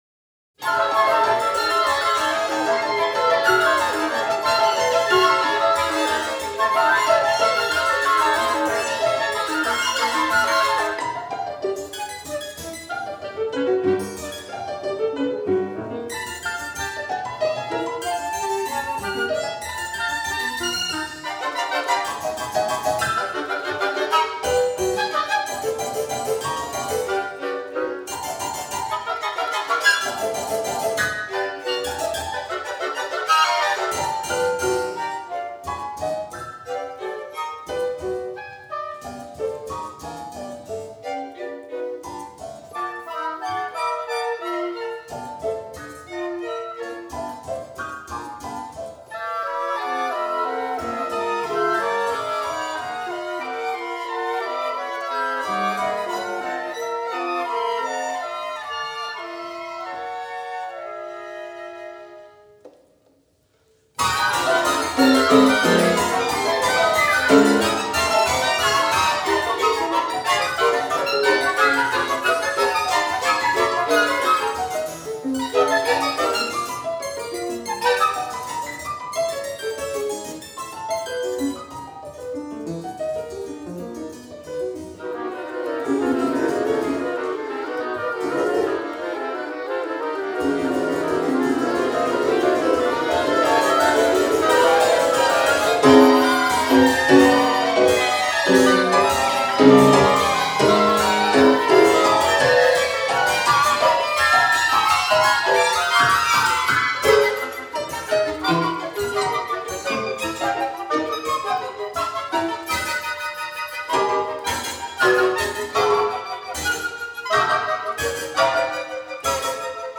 Llongüein para dos flautas, dos oboes, dos violines, clavecín y piano